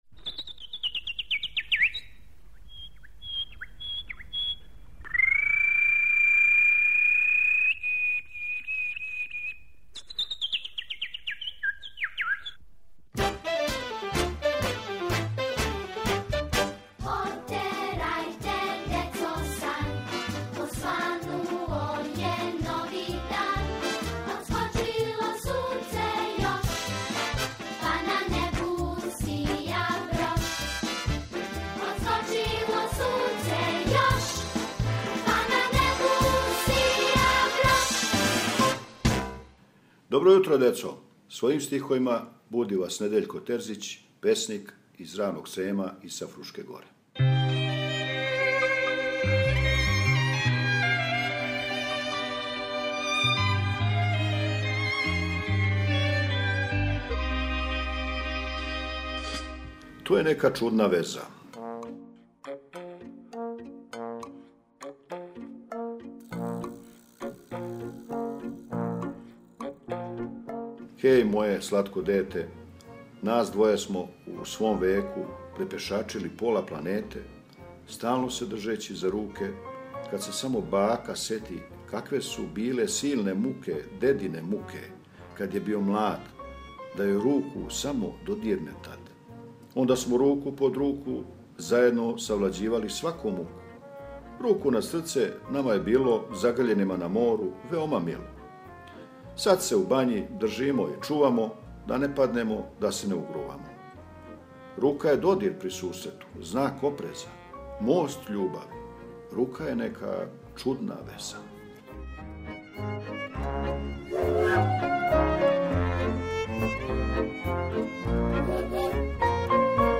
Поезија.